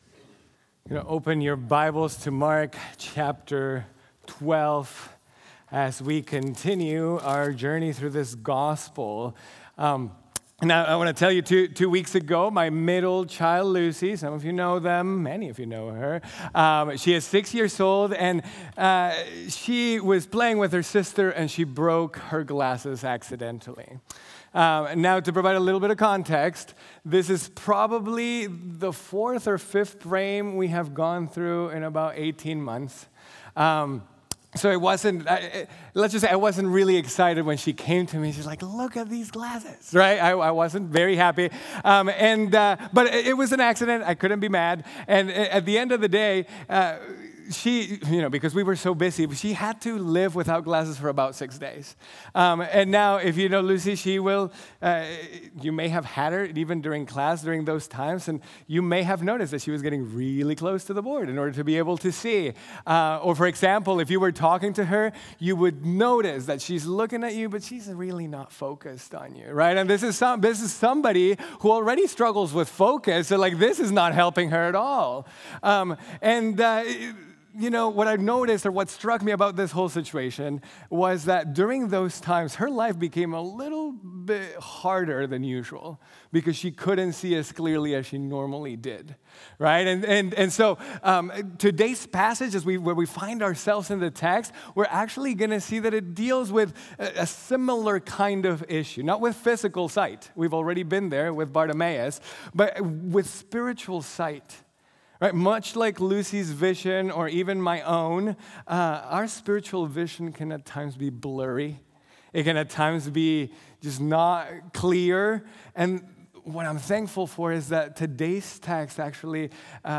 Seeing As God Sees | Baptist Church in Jamestown, Ohio, dedicated to a spirit of unity, prayer, and spiritual growth